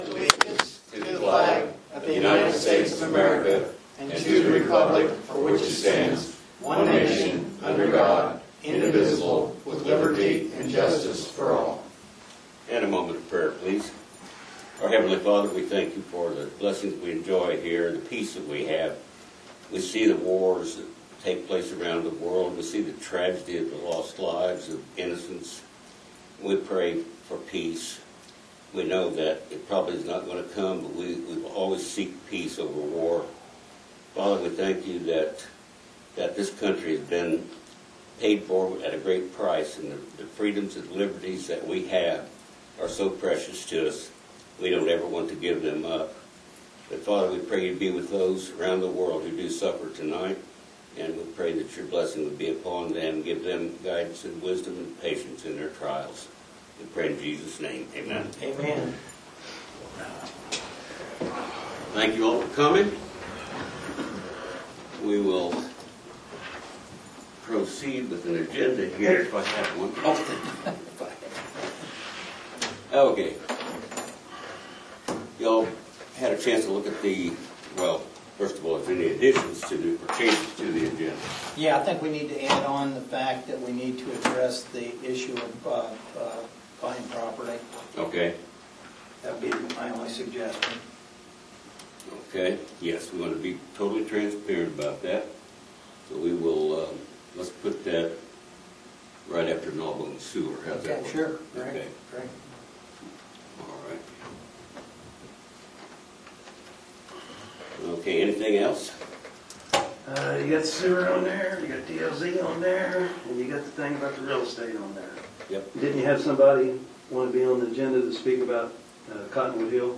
Agenda Commissioner Meeting Dec 27, 2023